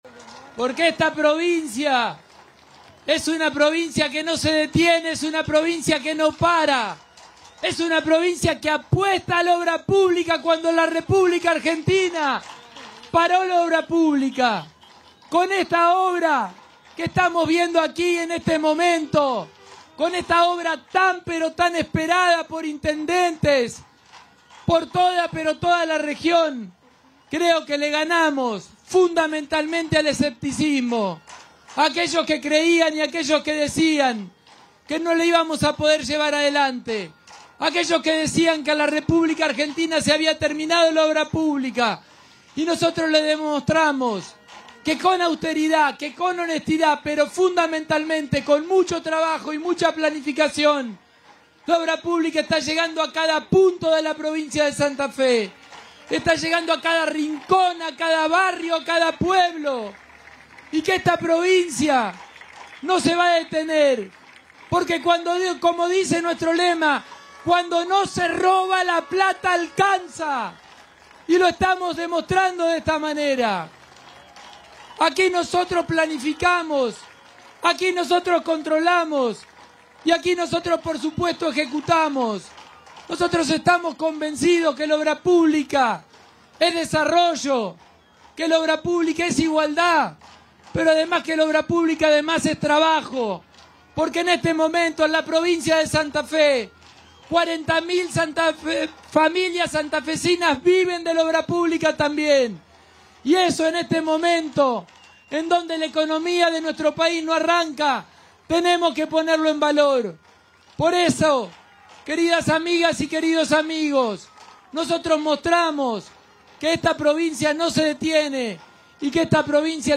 El gobernador Maximiliano Pullaro inauguró este lunes el tercer carril de la Autopista Rosario-Santa Fe.
Durante el acto, que se realizó sobre el puente del kilómetro 0 de la autopista, en el ingreso a la ciudad de Rosario, Pullaro resaltó que “esta Provincia no se detiene y apuesta a la obra pública, cuando la República Argentina paró las obras.
Fragmento del discurso del Gobernador